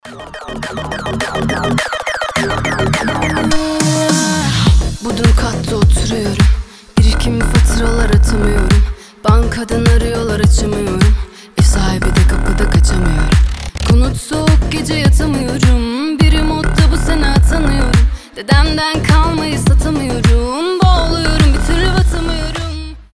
• Качество: 320, Stereo
женский вокал
мощные басы
Midtempo
Bass House
качающие